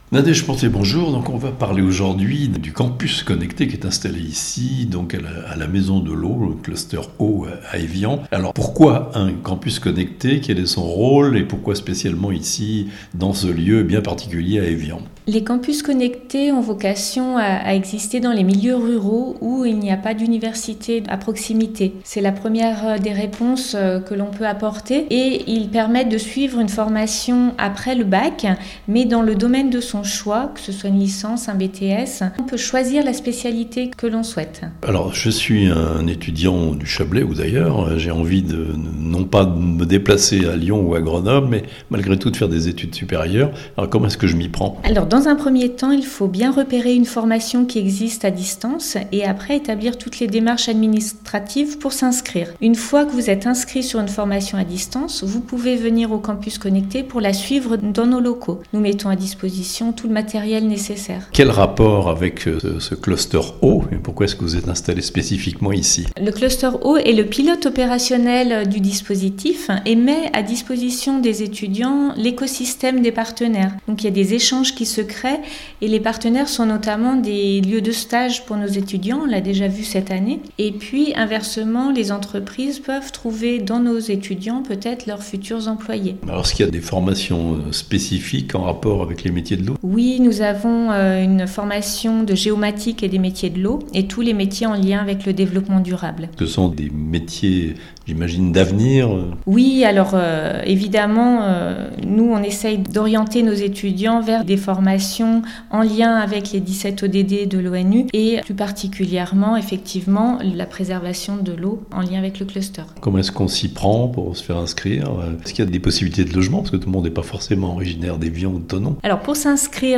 Grâce au Campus connecté du Chablais, entreprenez des études supérieures au plus près de chez vous (interview).